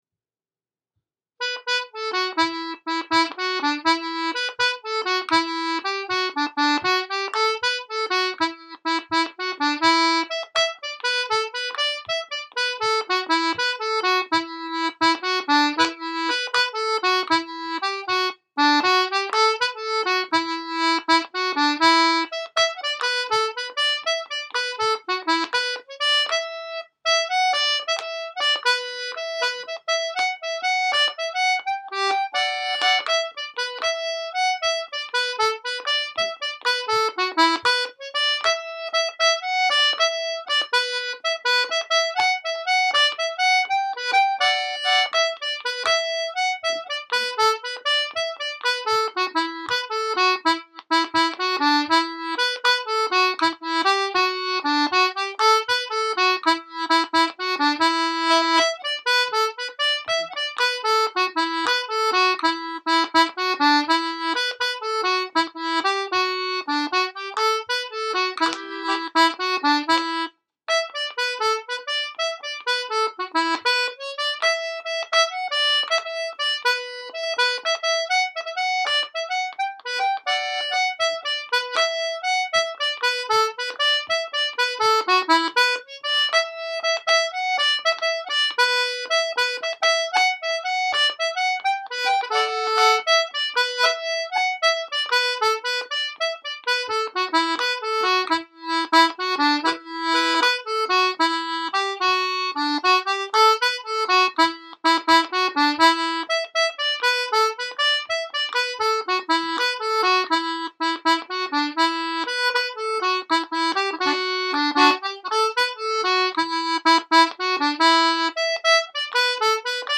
Jig Winnie Hayes_ (80 bpm)